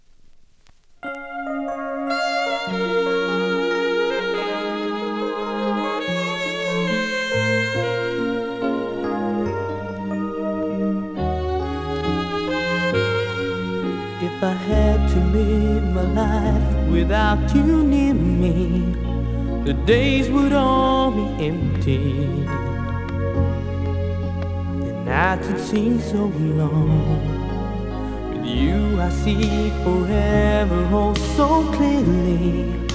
数発程度ノイズありますので試聴で確認下さい（その分値段下げての出品です）